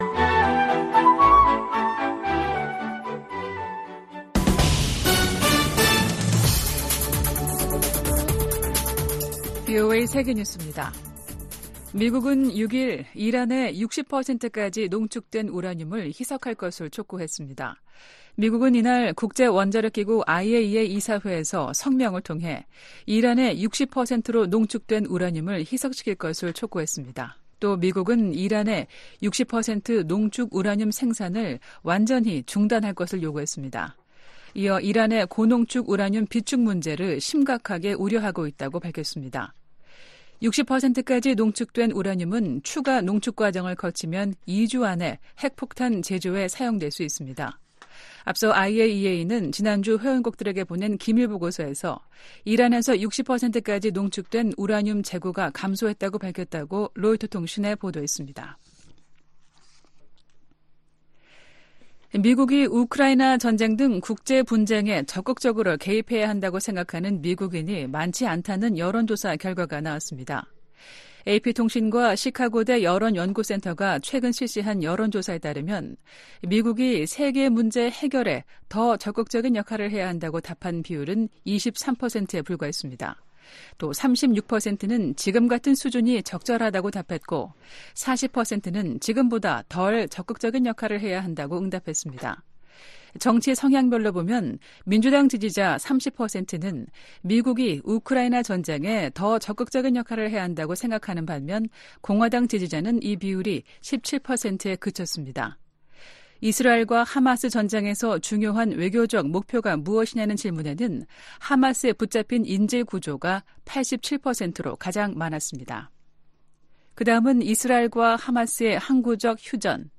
VOA 한국어 아침 뉴스 프로그램 '워싱턴 뉴스 광장' 2024년 3월 8일 방송입니다. 김정은 북한 국무위원장이 서부지구 작전훈련 기지를 방문해 전쟁준비 완성과 실전훈련 강화를 강조했다고 관영 매체들이 보도했습니다. 미국 정부는 현재 진행 중인 미한 연합훈련이 전쟁연습이라는 북한의 주장을 일축했습니다. 미국이 국제원자력기구(IAEA) 이사회에서 북한-러시아 탄도미사일 거래를 강력 규탄했습니다.